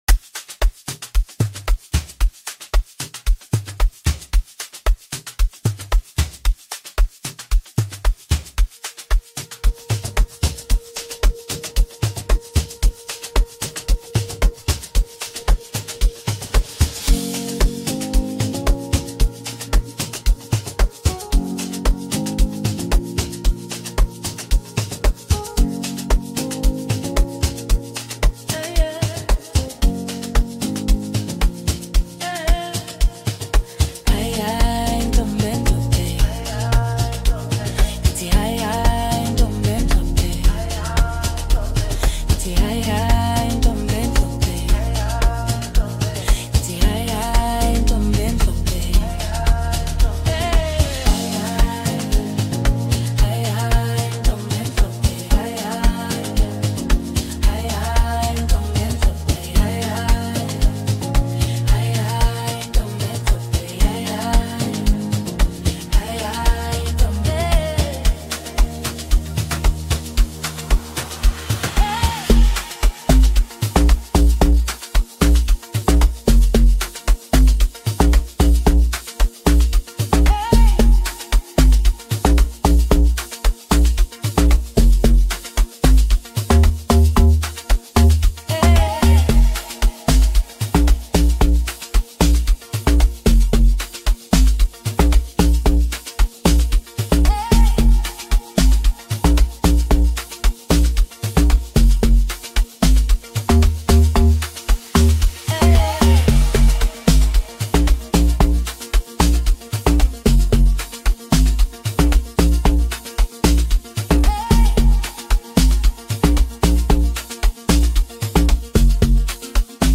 catchy melodies